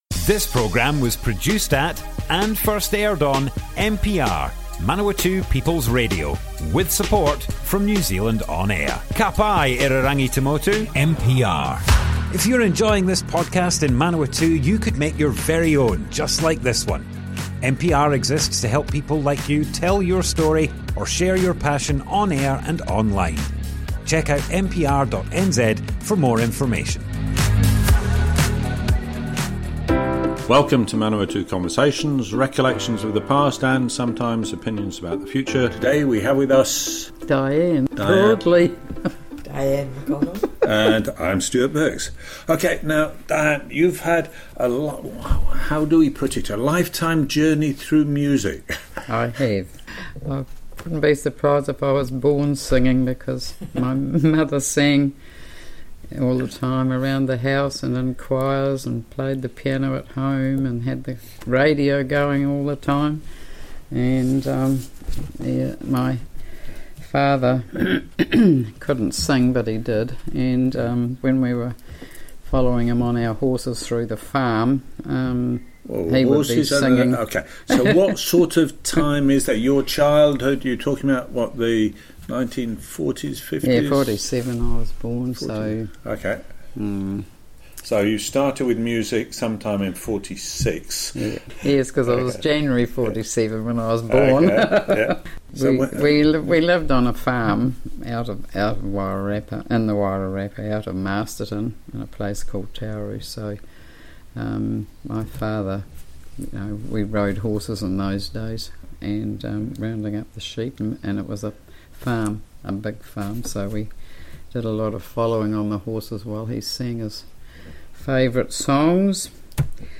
Manawatu Conversations More Info → Description Broadcast on Manawatu People's Radio, 7th October 2025.
oral history